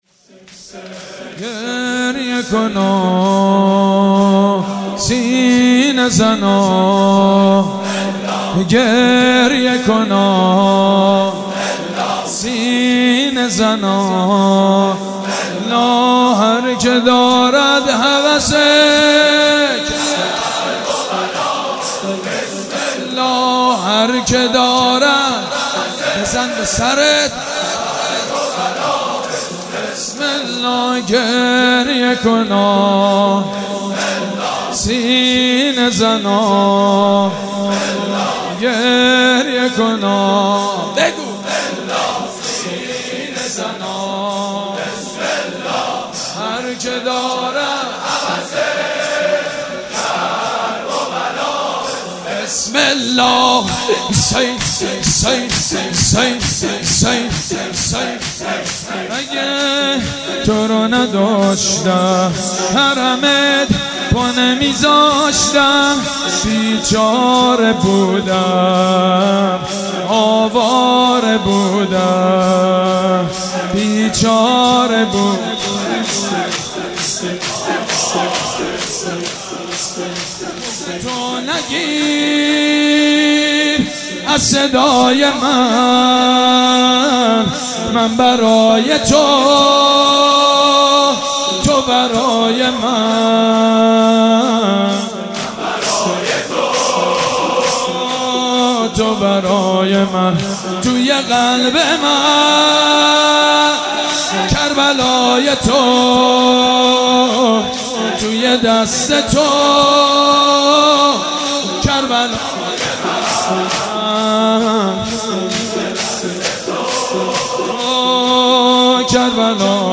شور کربلایی حسین طاهری